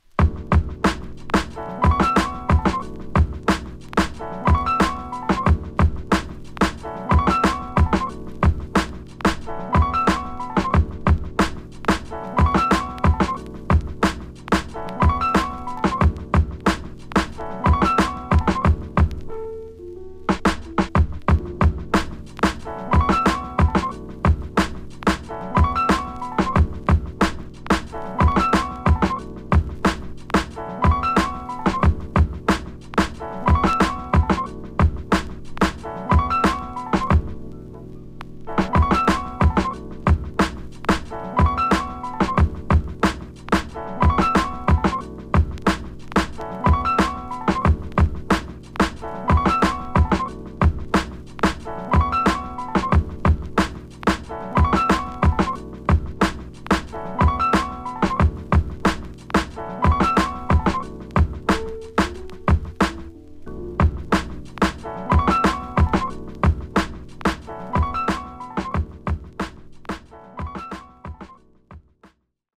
2. > HIPHOP